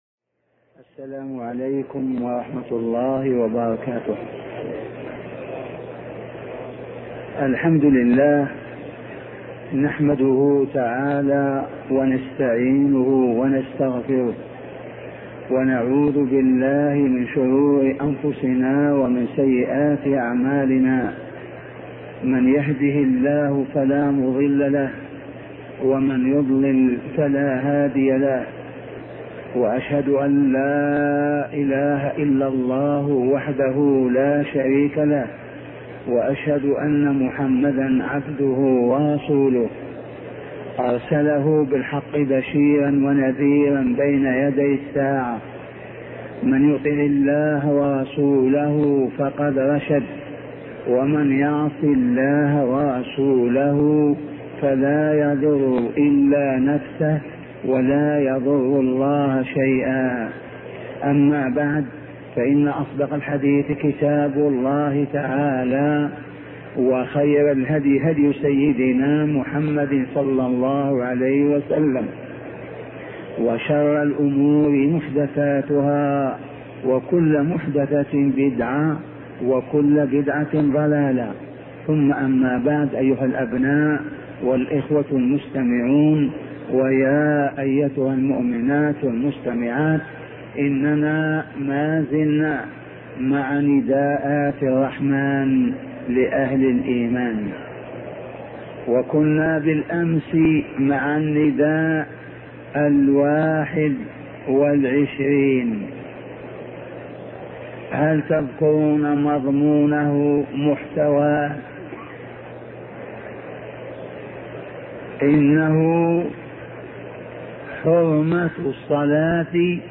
شبكة المعرفة الإسلامية | الدروس | نداءات الرحمن لأهل الإيمان 22 |أبوبكر الجزائري